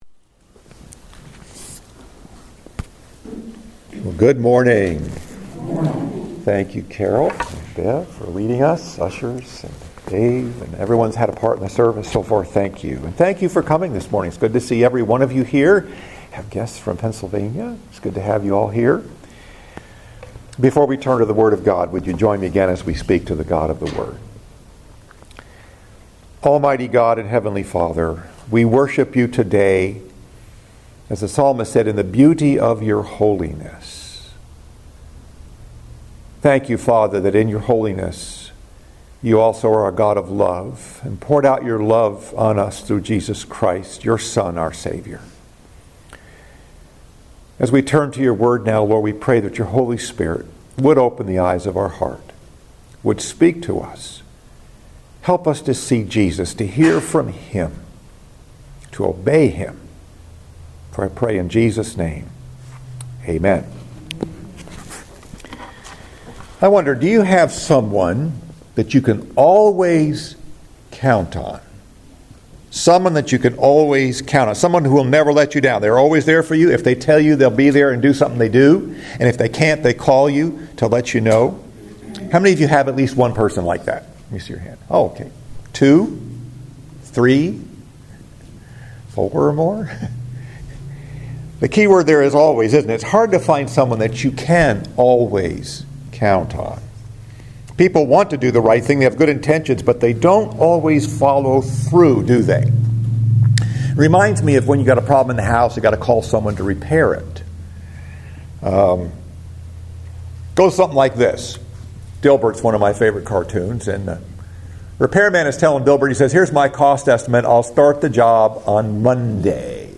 Message: “The Next Step” Part 1 Scripture: John 21:1-6 First Sunday after Easter